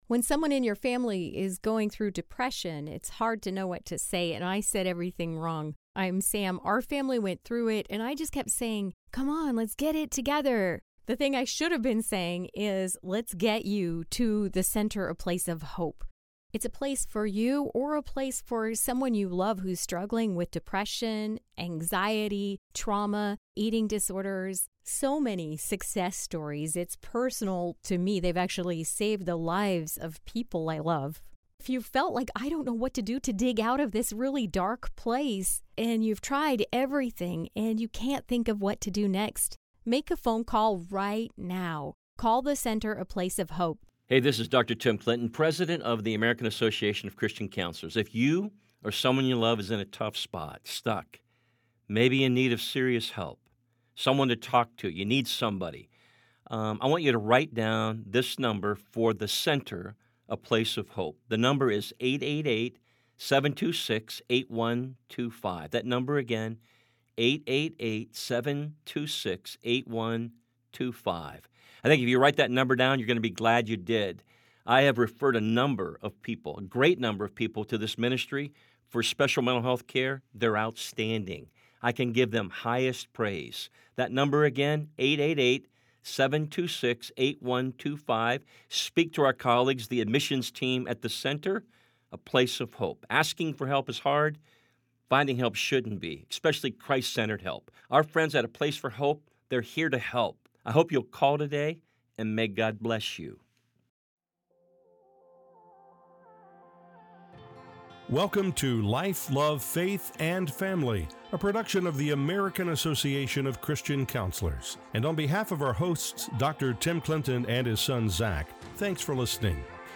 for an honest conversation about mental health in the church.